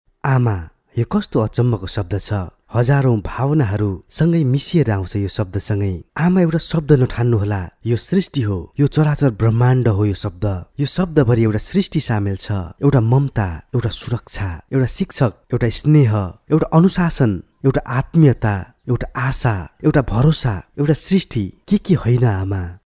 Nepali voice overs talent.
Nepali female voiceovers   Nepali male voice talents